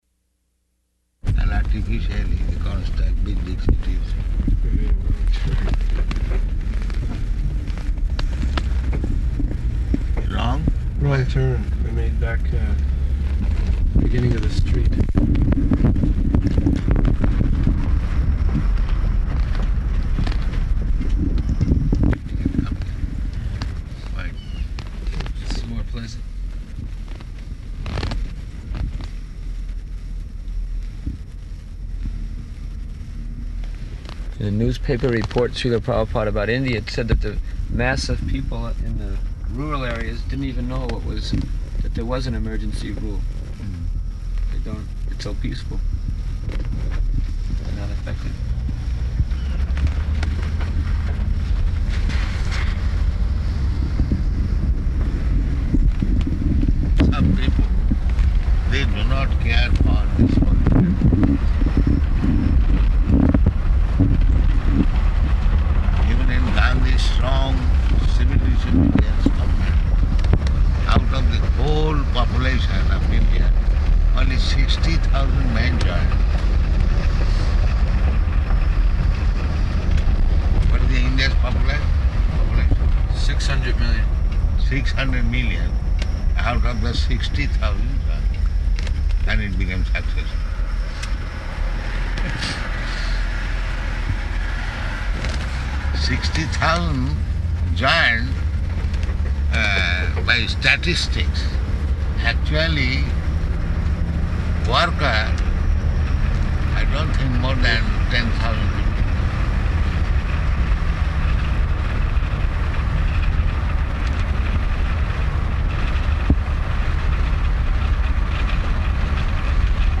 Type: Walk
Location: Dallas